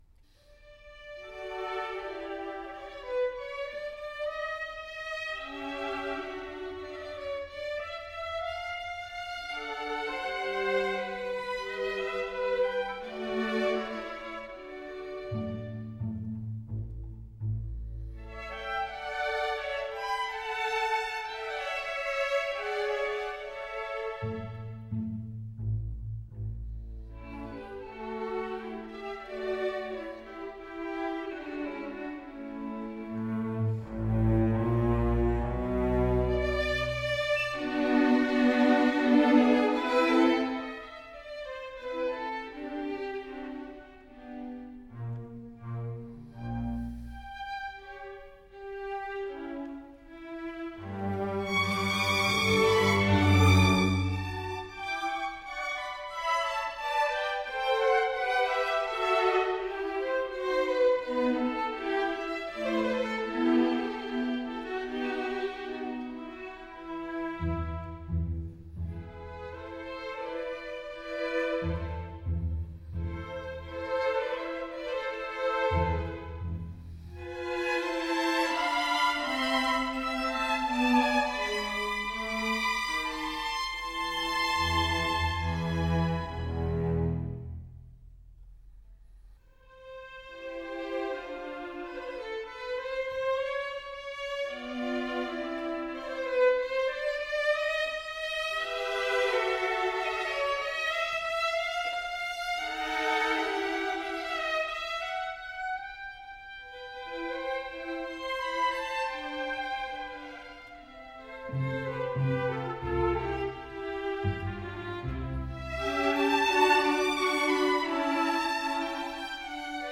Poco adagio. From “Sinfonia no. 2 in B flat major”.
Camerata Bern